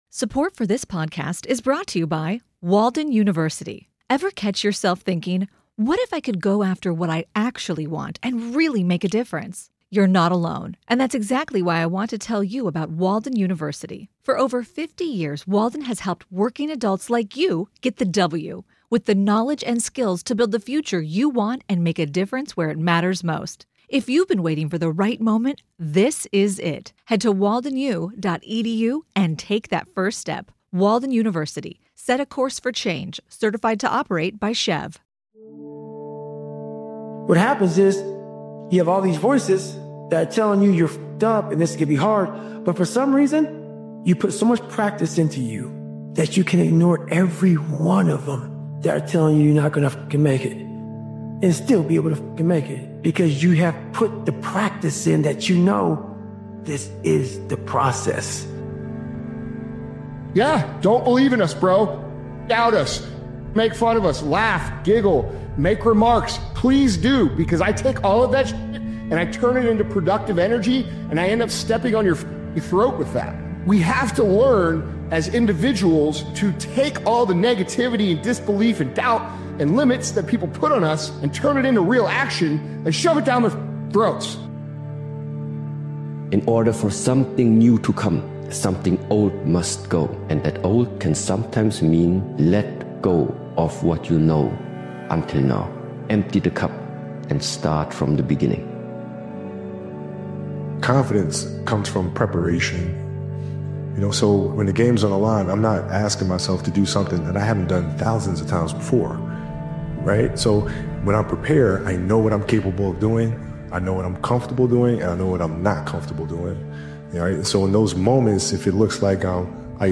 Powerful Motivational Speeches Video is a relentless and high-energy motivational video created and edited by Daily Motivations. This intense motivational speeches compilation asks the question that defines success: how far are you truly willing to go for what you want?